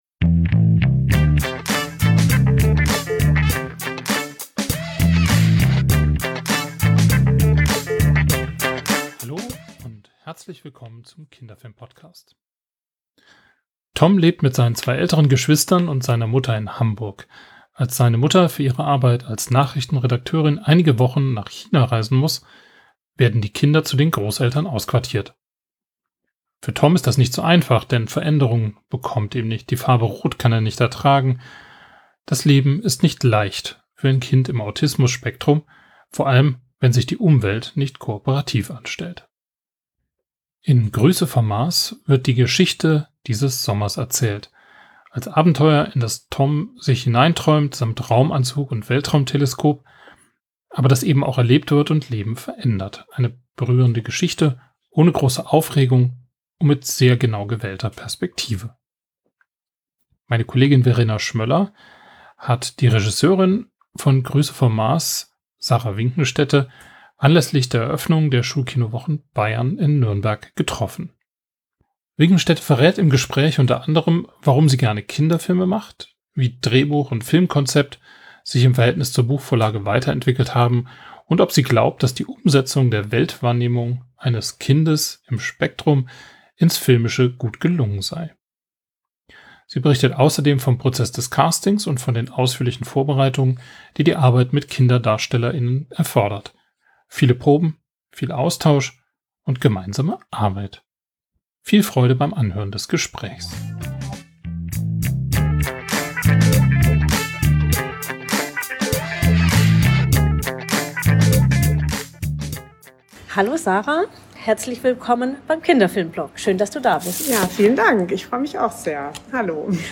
im Gespräch zu “Grüße vom Mars”